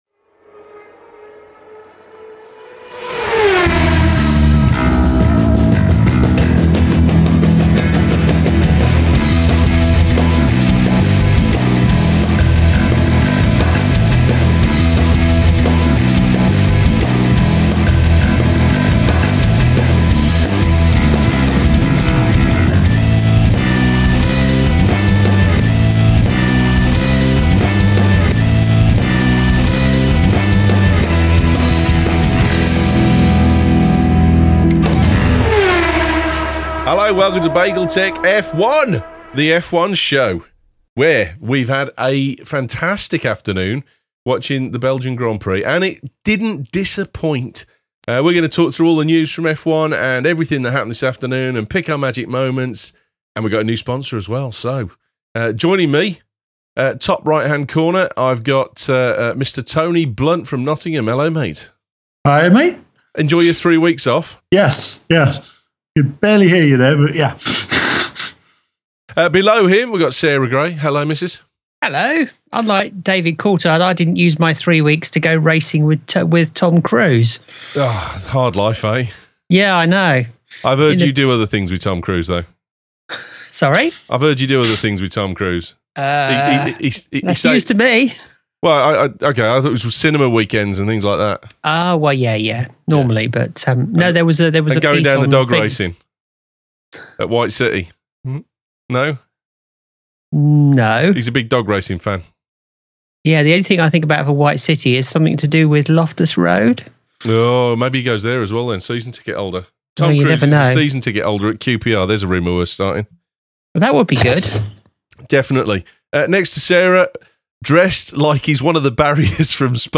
We publish an open chat after each week’s race and voice our thoughts and feelings on the ins and outs of the race We pick our Magic Moments and give our predictions for the Italian Grand Prix in 2 weeks.